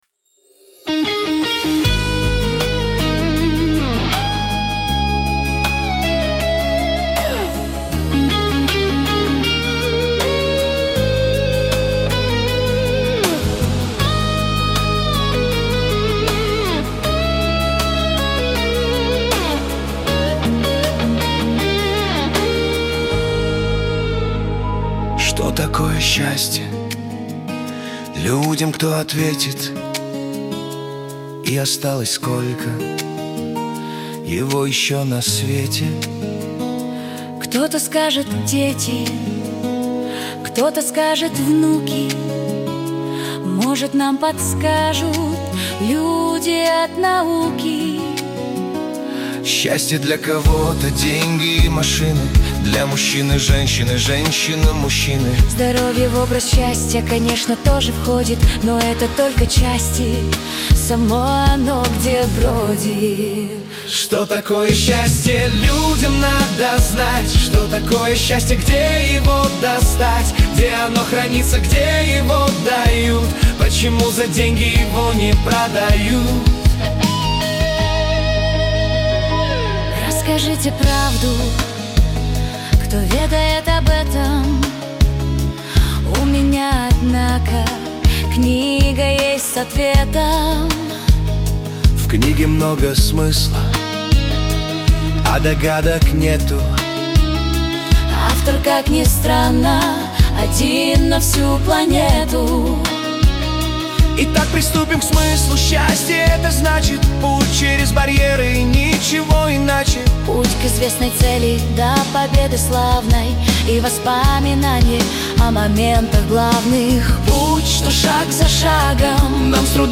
Лирика
Веселая музыка